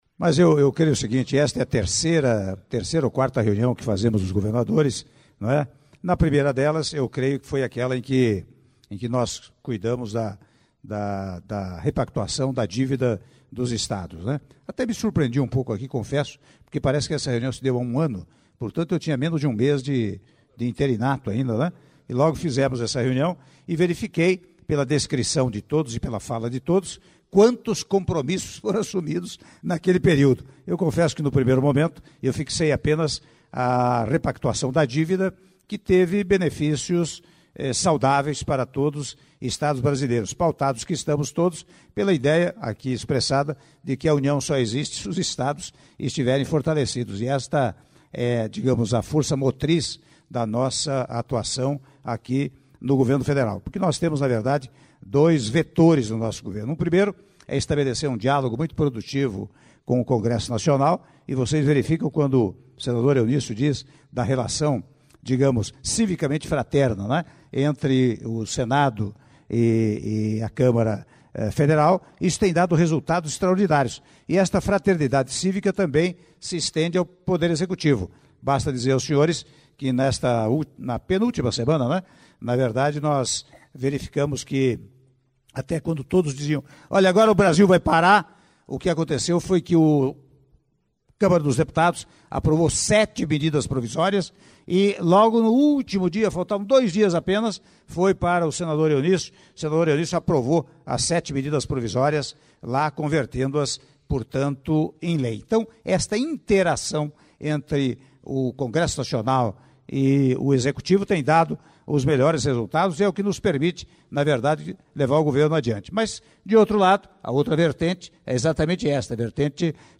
Áudio do Presidente da República, Michel Temer, no encerramento do jantar com Governadores - Brasília/DF (05min38s)